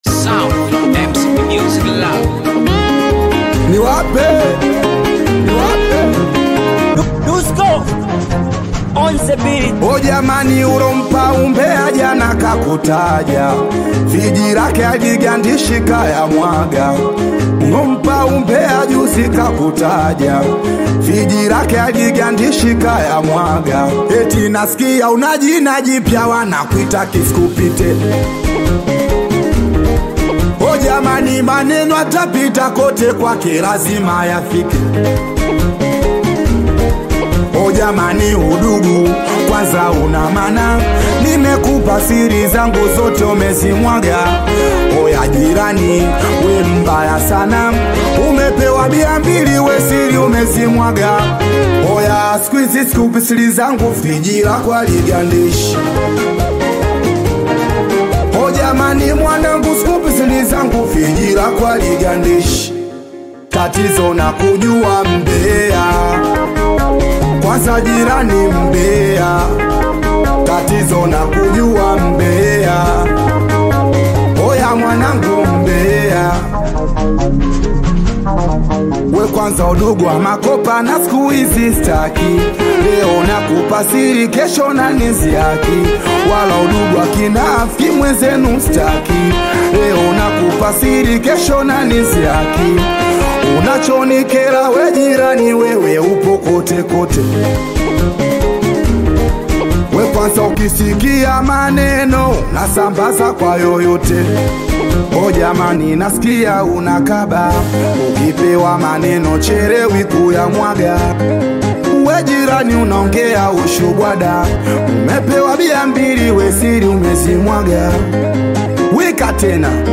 Tanzanian bongo flava and singeli singer and songwriter